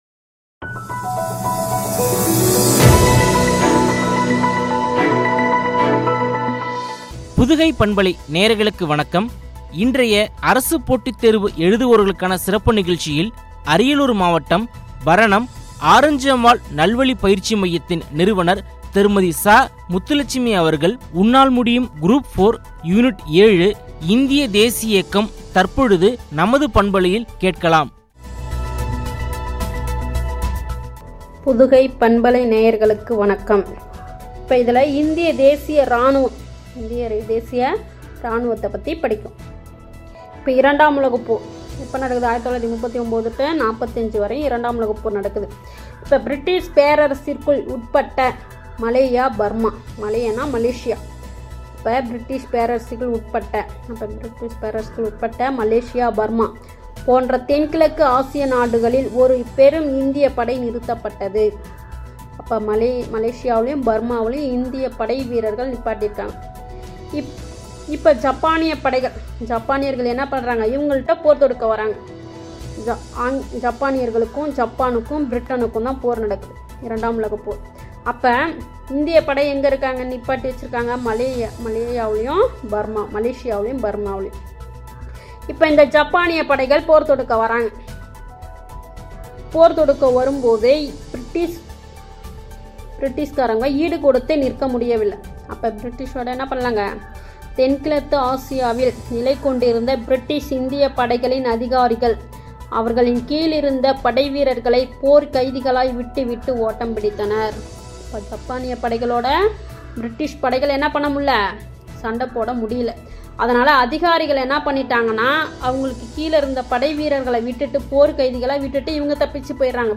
(குரூப் 4 – Unit 07 – இந்திய தேசிய இயக்கம் – பகுதி 14), குறித்து வழங்கிய உரையாடல்.